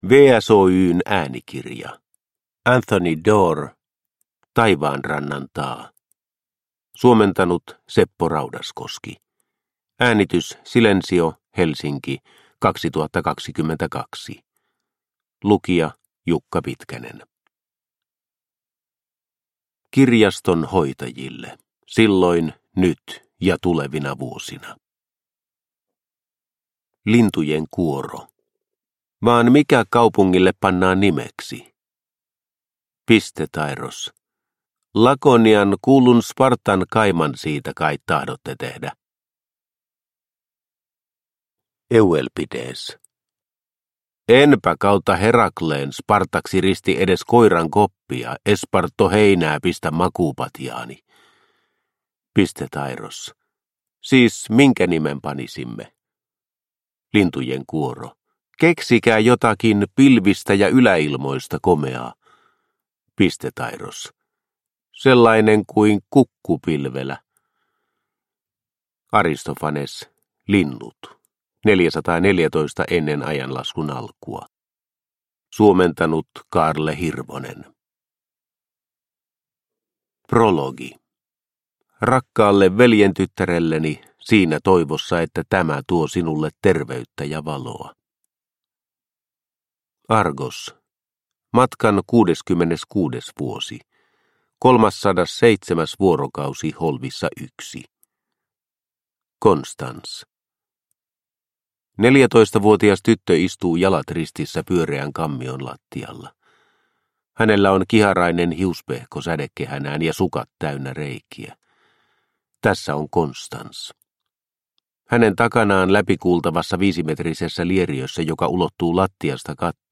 Taivaanrannan taa – Ljudbok – Laddas ner